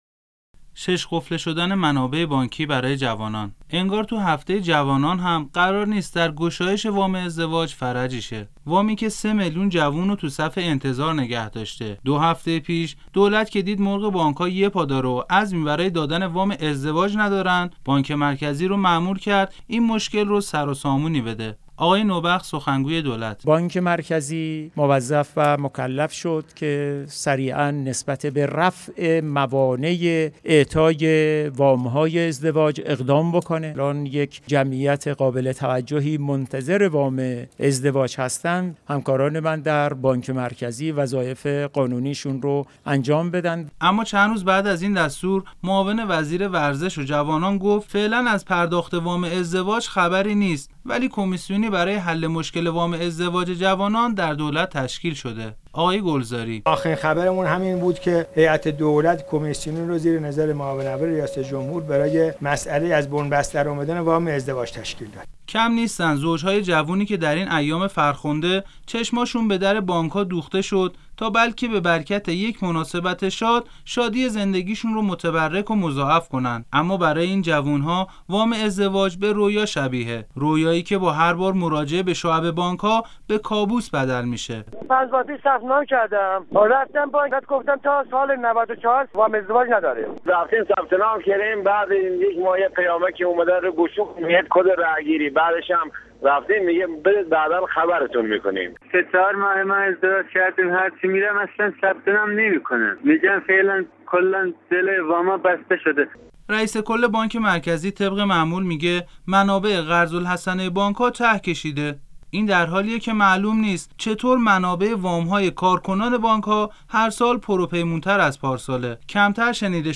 این گزارش که رادیو ایران آن را پخش کرده است بشنوید: